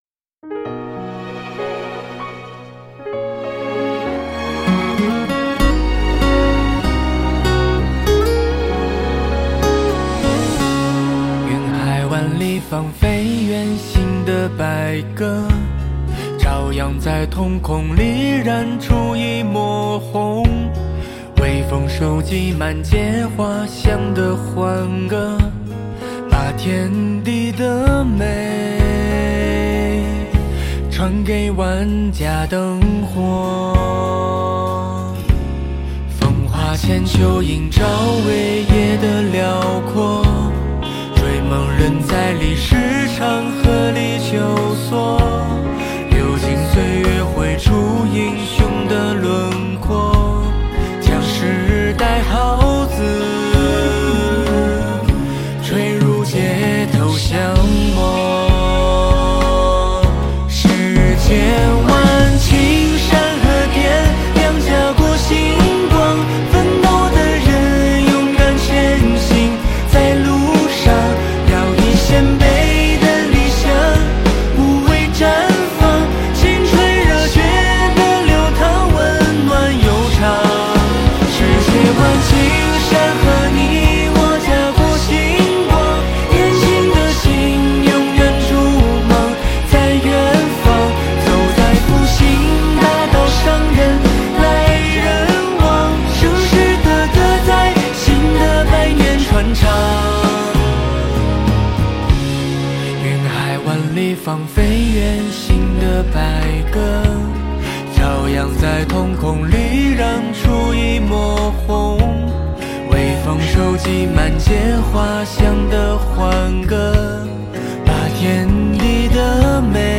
他的聲音非常溫柔